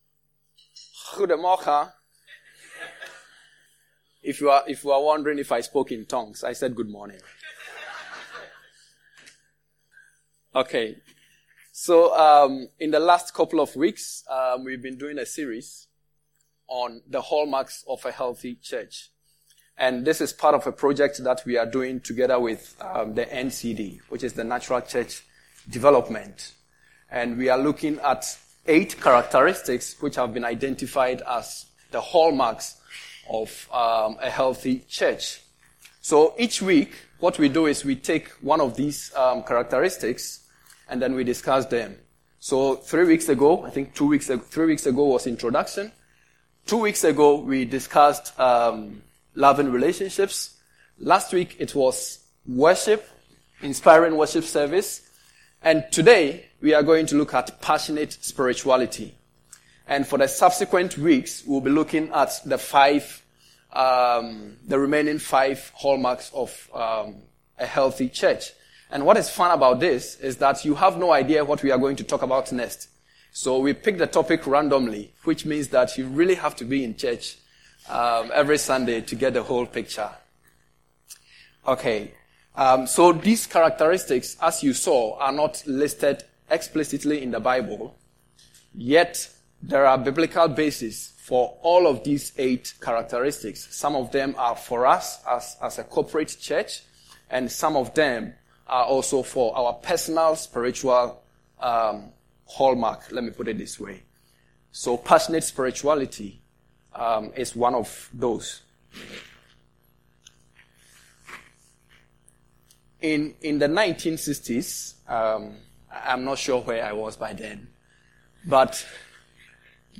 Vineyard Groningen Sermons